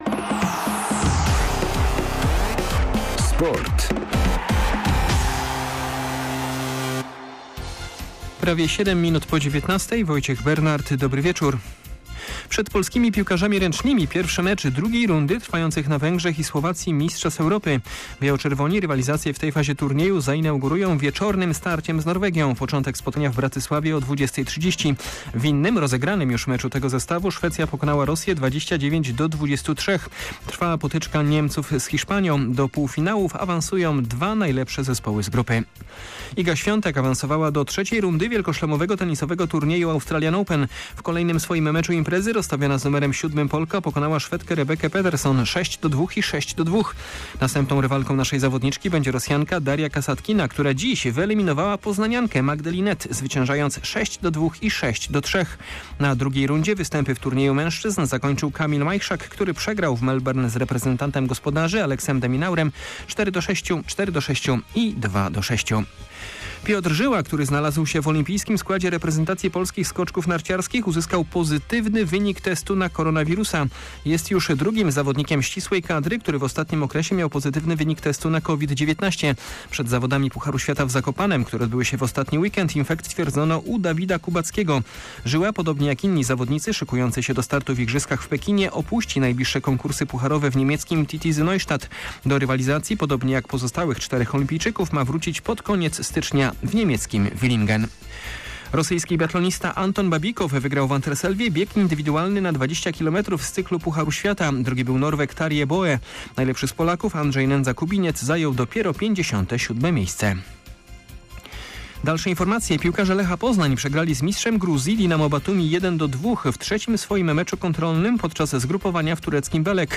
20.01.2022 SERWIS SPORTOWY GODZ. 19:05